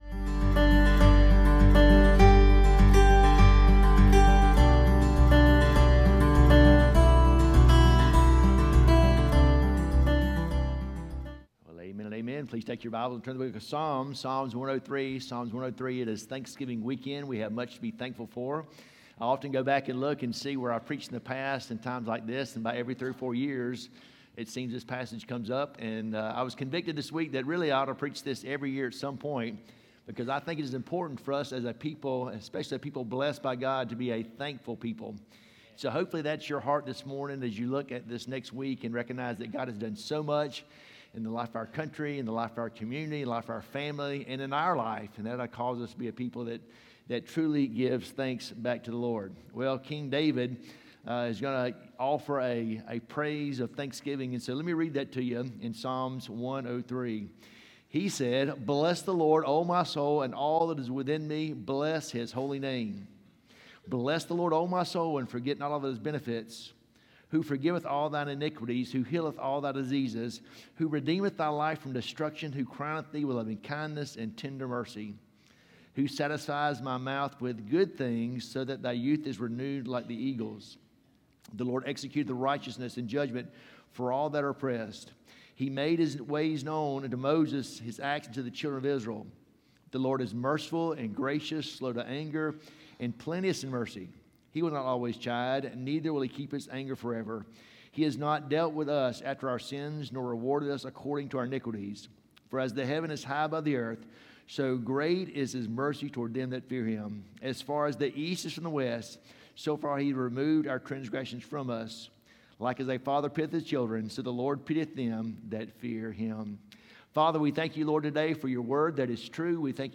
Sermons 2 – Sardis Baptist Church | Worthington Springs, FL